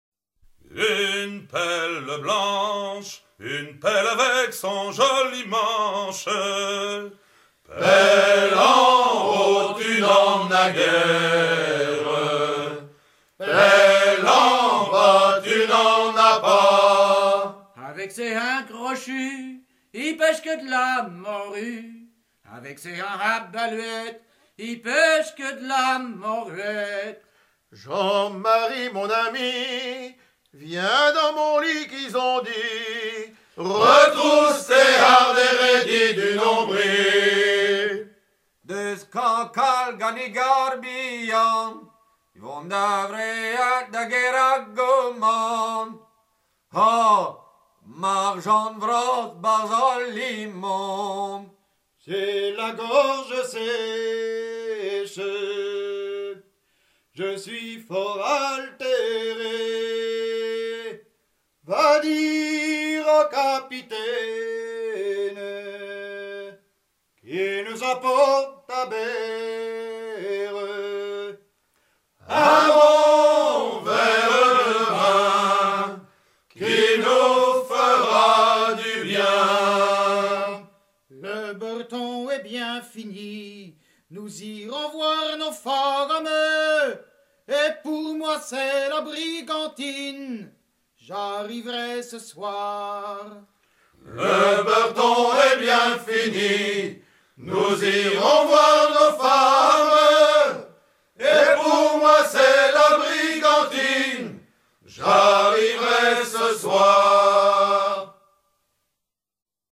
Suite de chants à curer les runs recueillis à Fécamp, Cancale, Saint-Malo ET Paimpol
Haleurs ou dameurs travaillant ensemble rythmiquement
Pièce musicale éditée